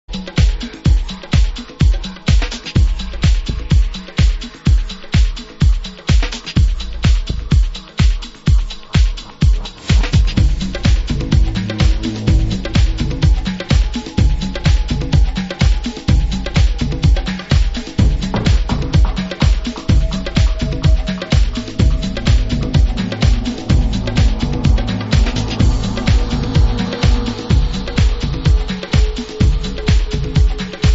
Deep Progressive House And Trance Mix